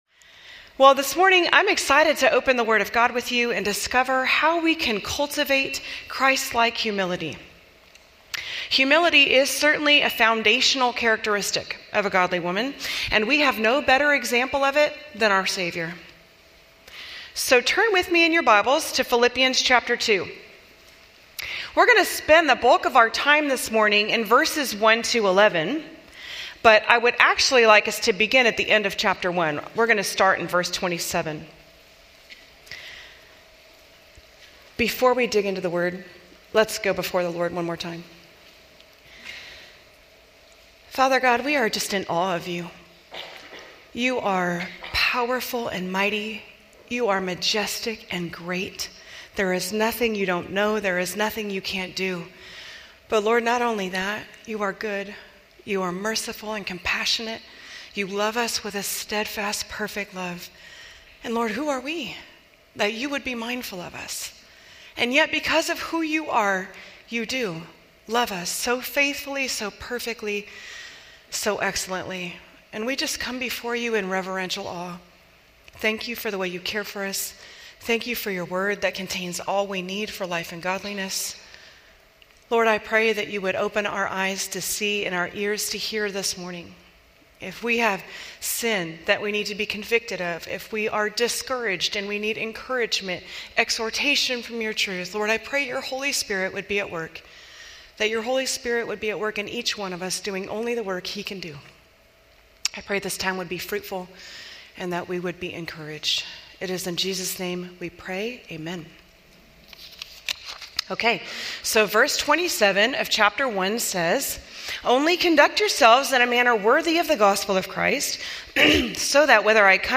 Women Women's Fall Conference - 2024 Audio Video ◀ Prev Series List Next ▶ Previous 1.